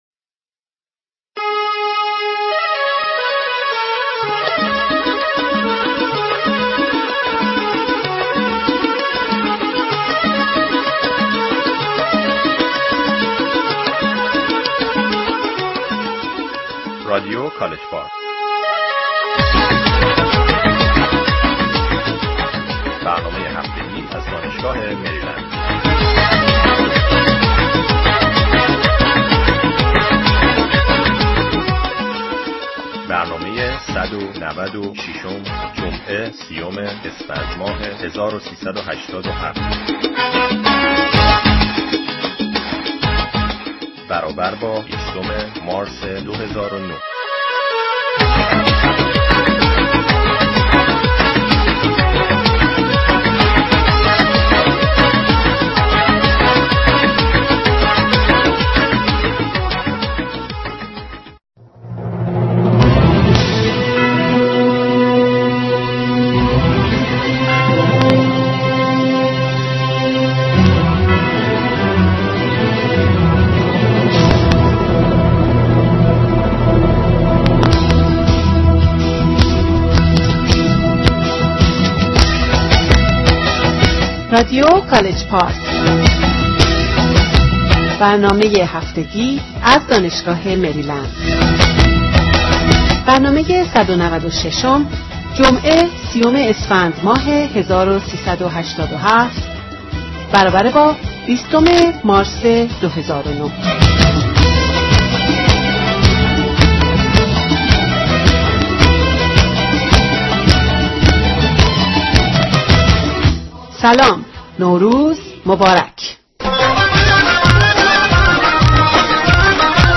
به قول معروف اشکالات فنی بوده و امیدواریم به زودی برطرف بشه.